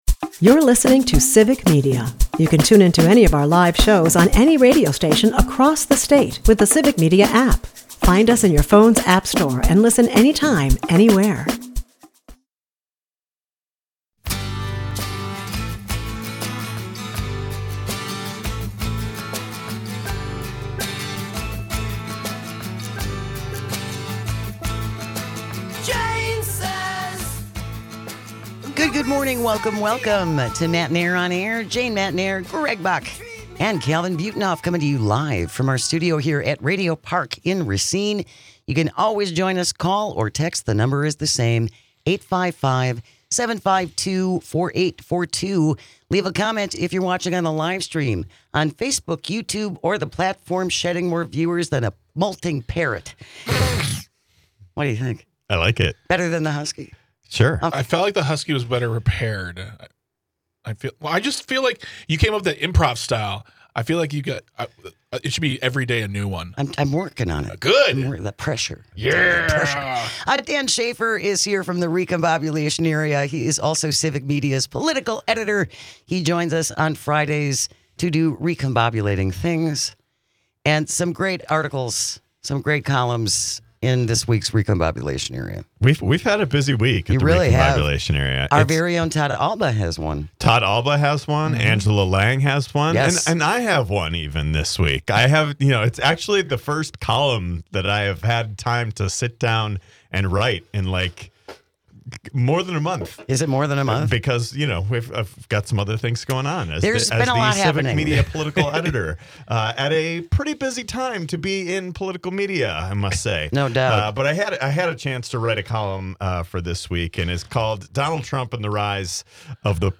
The texts and calls roll in on this one and it's a hard road for the Democrats going forward.